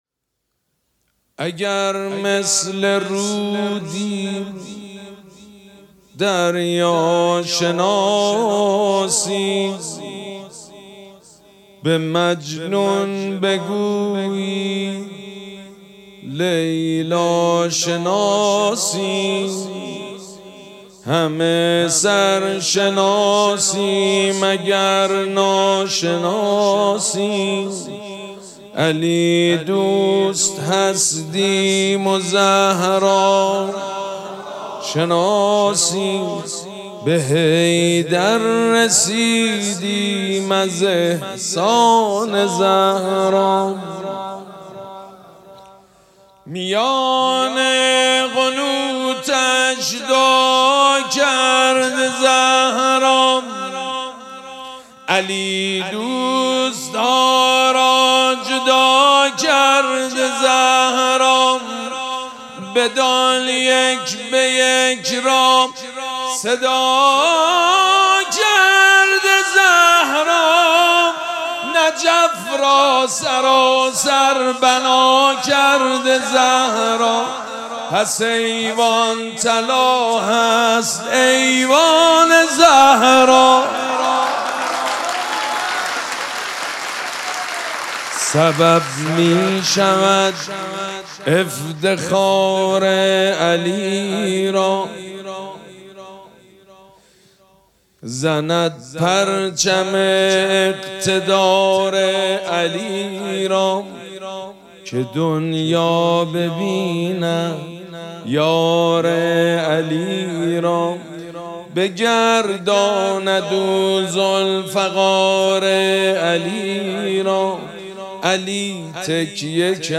مراسم جشن ولادت حضرت زهرا سلام الله علیها
مدح
حاج سید مجید بنی فاطمه